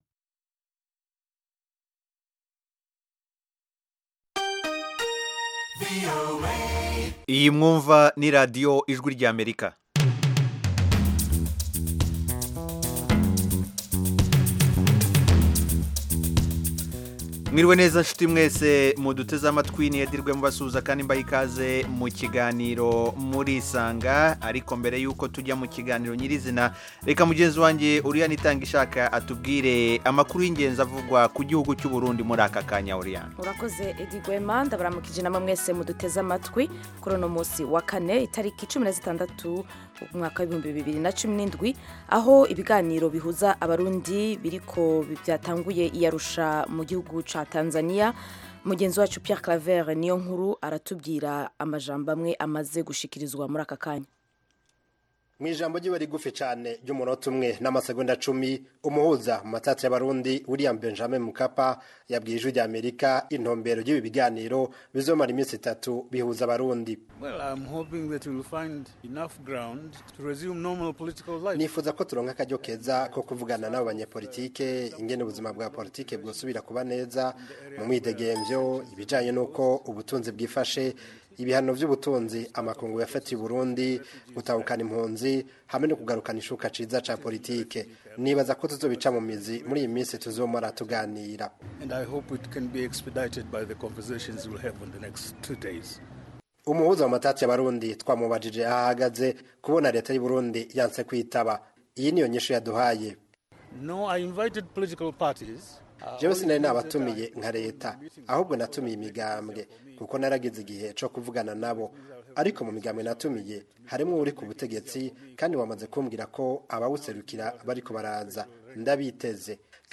Murisanga itumira umutumirwa, cyangwa abatumirwa kugirango baganira n'abakunzi ba Radiyo Ijwi ry'Amerika. Aha duha ijambo abantu bivufa kuganira n'abatumirwa bacu, batanga ibisobanuro ku bibazo binyuranye bireba ubuzima b'abantu.